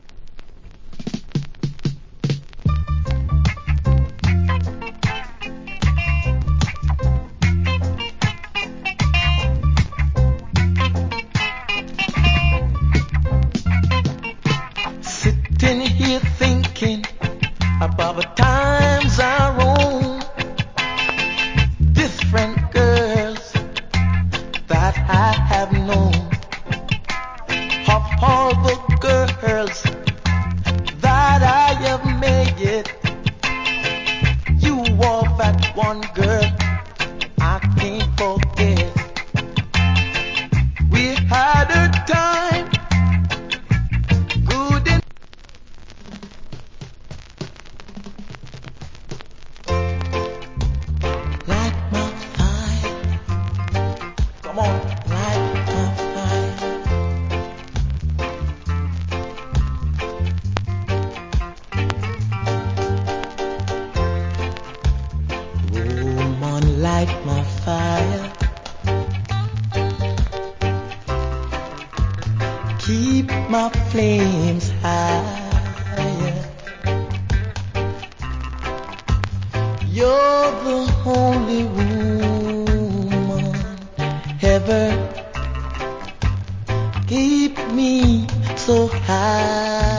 Nice UK Reggae Vocal.